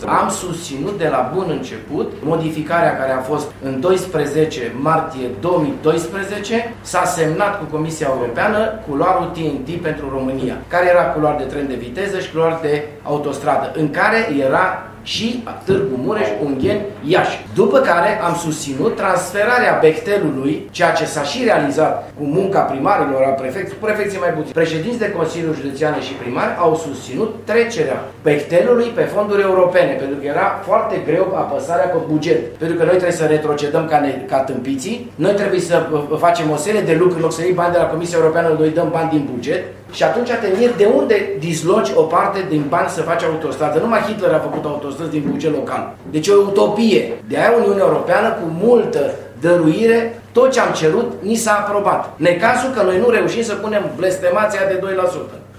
Primarul Dorin Florea i-a primit pe ieșeni și i-a asigurat că îi va sprijini atât cât îi permit atribuțiile: